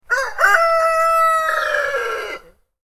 Rooster Morning Sound Effect
Cock crowing early in the morning on a rural farm. Country morning sounds.
Rooster-morning-sound-effect.mp3